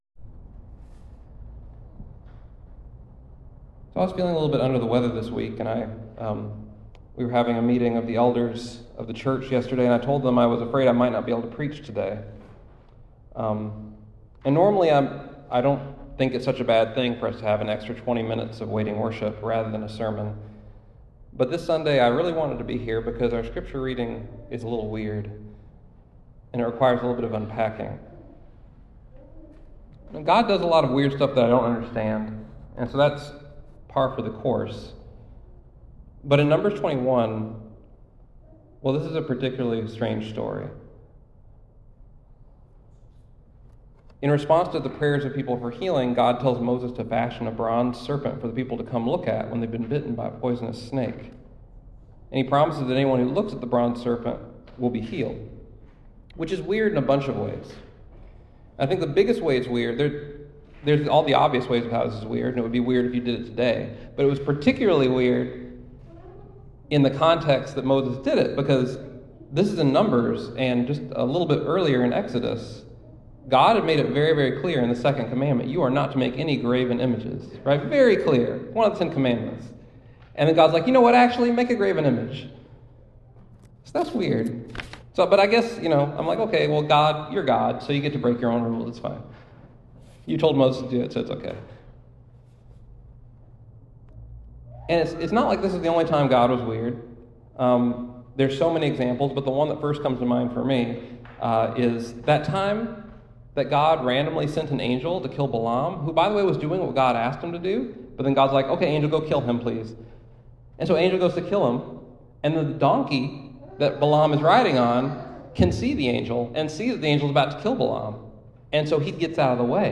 BFC-Sermon-3.10.24-processed.mp3